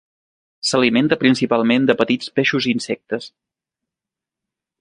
Pronúnciase como (IPA) [ˈpe.ʃus]